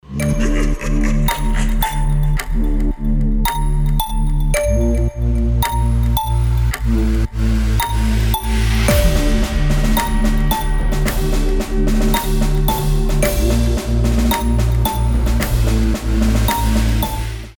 • Качество: 320, Stereo
пугающие
смех
Сирена
барабаны
жуткие